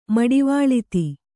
♪ maḍivāḷiti